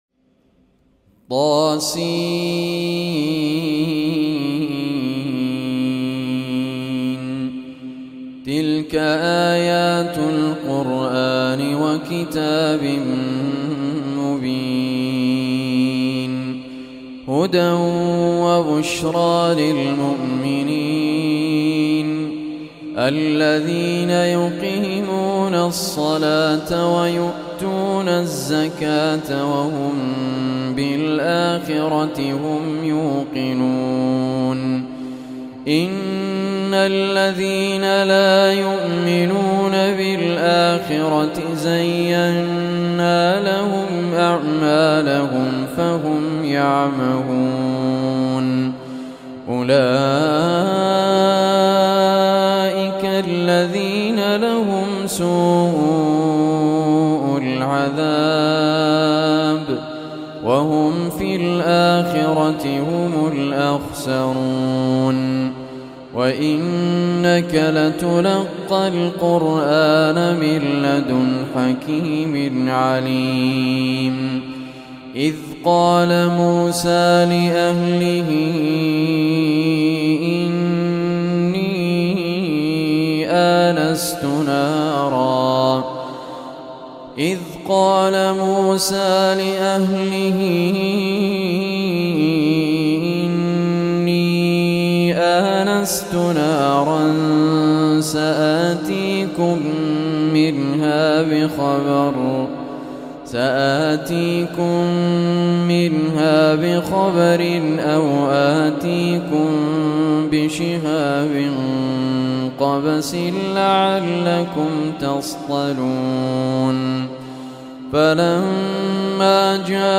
Surah An Naml Recitation by Sheikh Raad Al Kurdi
Surah Naml, listen or play online mp3 tilawat / recitation in the beautiful voice of Sheikh Raad Al Kurdi.